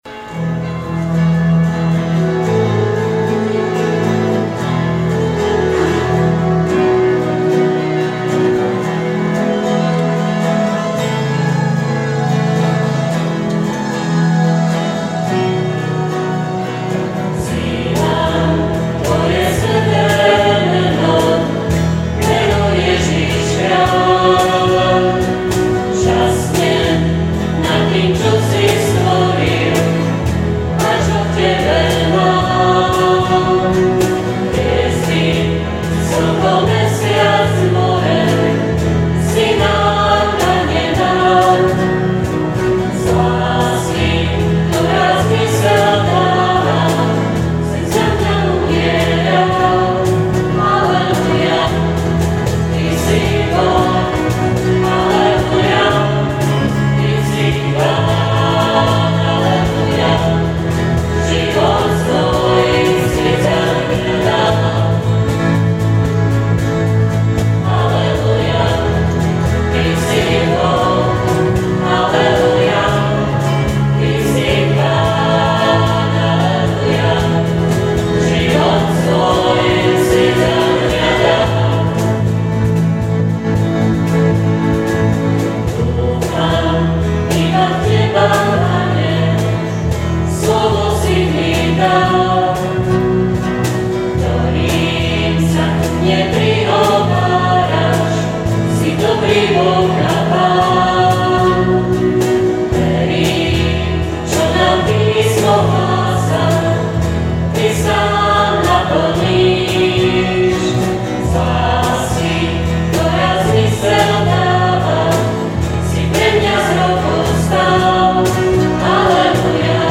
Zbor sv. Petra a Pavla v Seni
živé nahrávky piesní zo sv. omší :